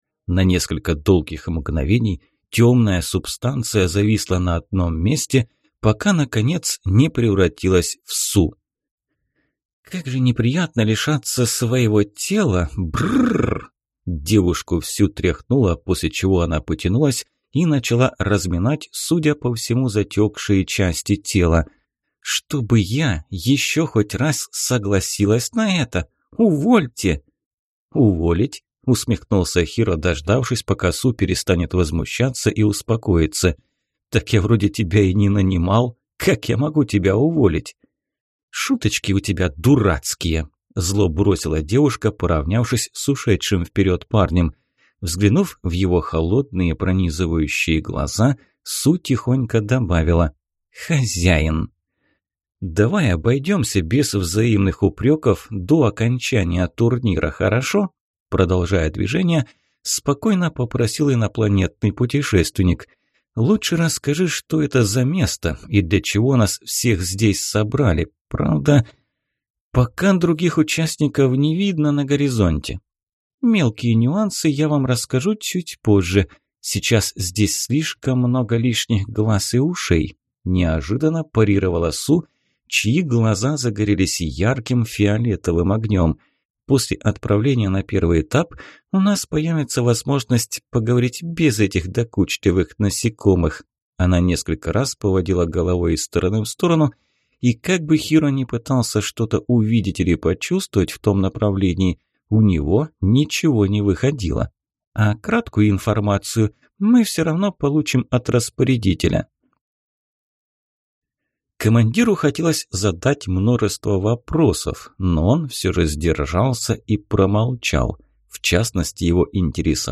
Аудиокнига Моя Космическая Станция. Книга 4. Право Претендента | Библиотека аудиокниг